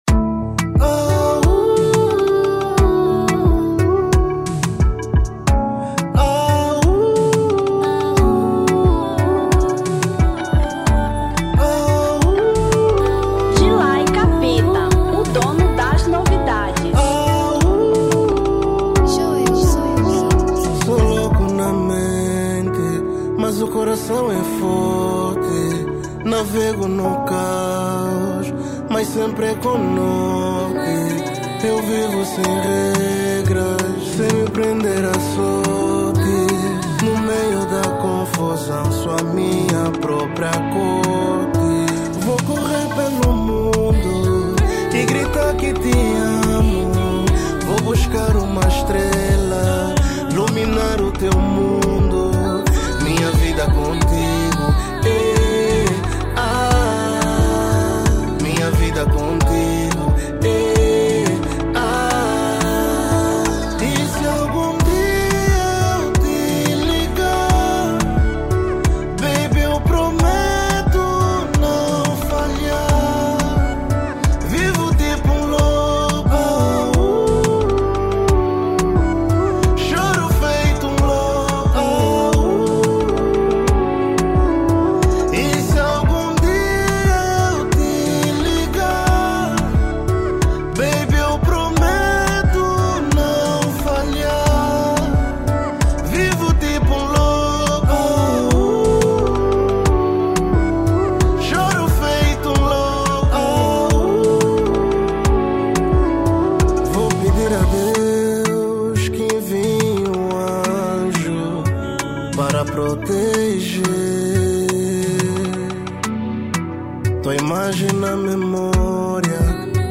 Zouk